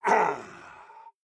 tt_s_ara_cmg_bossCogAngry.mp3